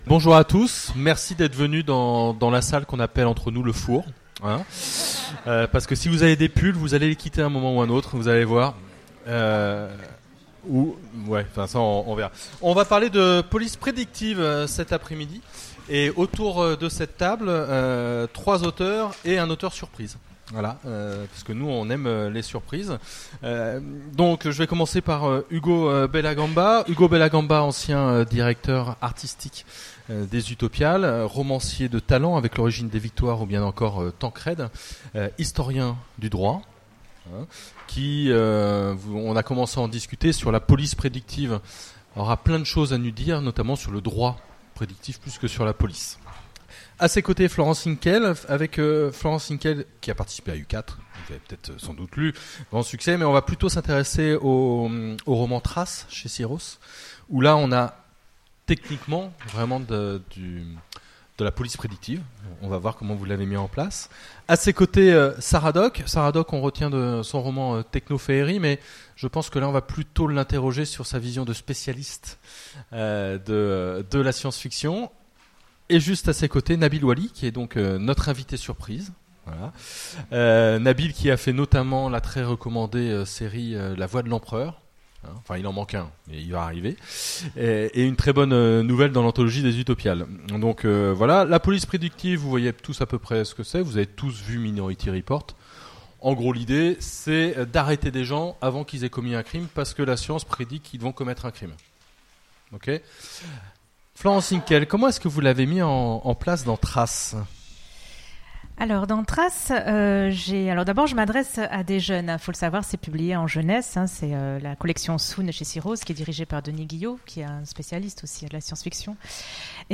Utopiales 2017 : Conférence La police prédictive